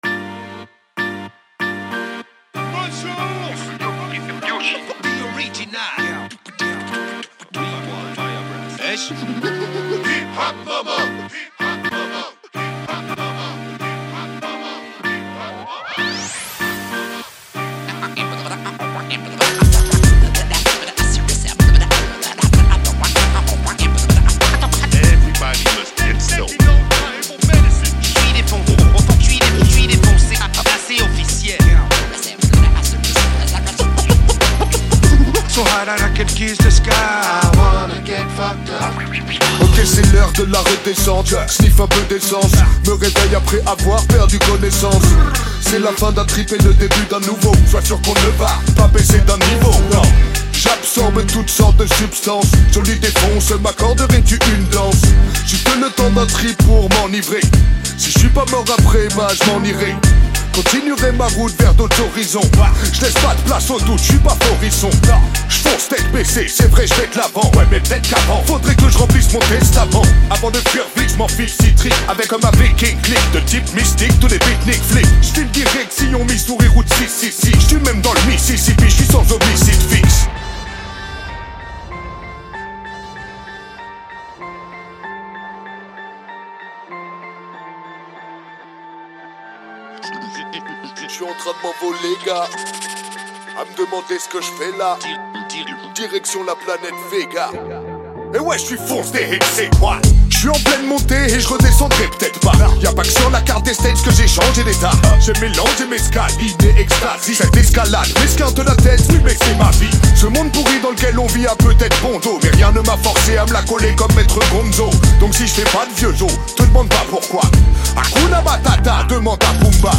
0/100 Genres : raï Écouter sur Spotify